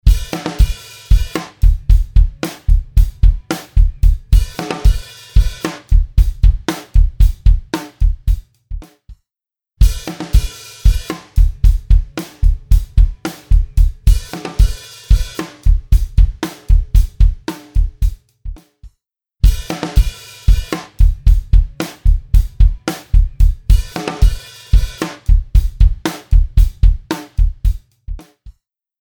パワフルなディストーション＆サチュレーション・エンジン
CrushStation | Snare | Preset: SnareStation
CrushStation-Eventide-Snare-Drum-SnareStation.mp3